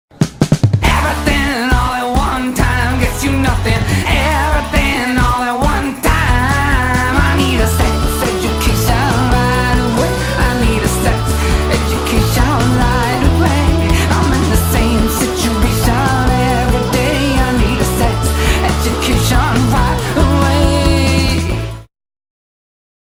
Category: BGM